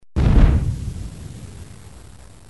دانلود صدای بمب و موشک 18 از ساعد نیوز با لینک مستقیم و کیفیت بالا
جلوه های صوتی